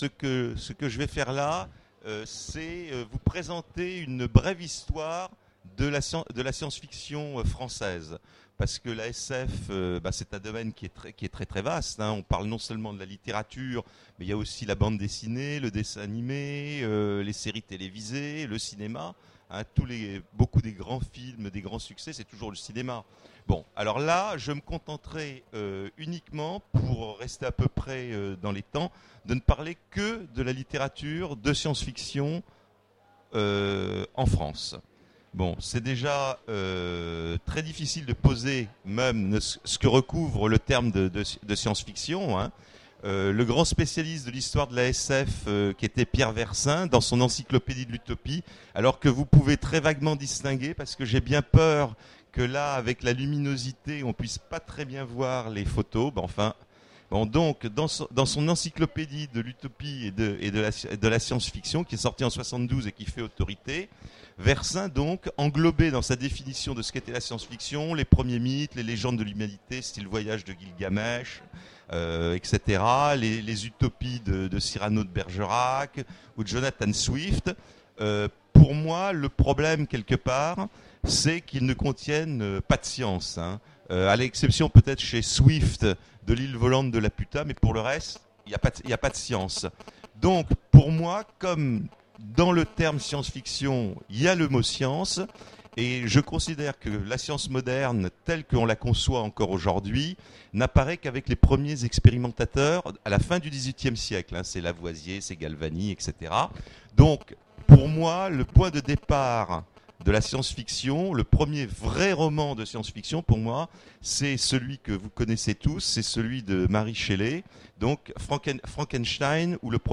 Voici l'enregistrement de la conférence sur une histoire de la SF française aux Futuriales 2010